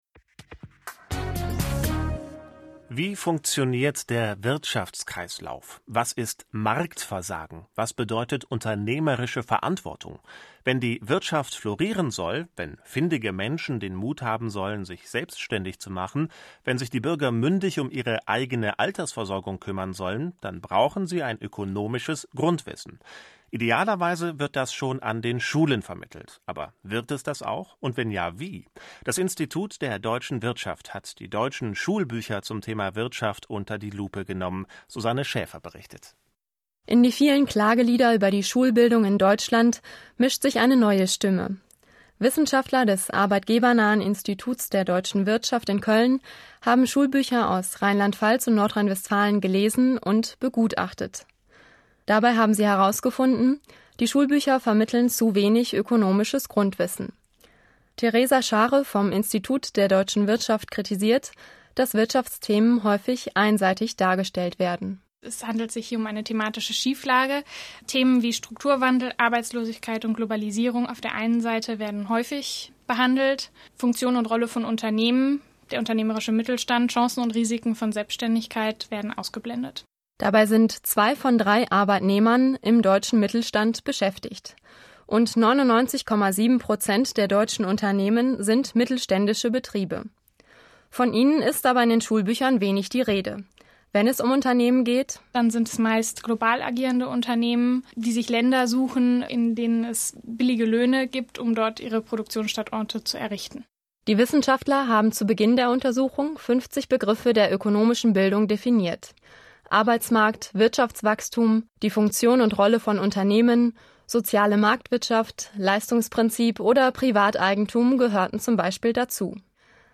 Näheres dazu im Interview in der Deutschen Welle.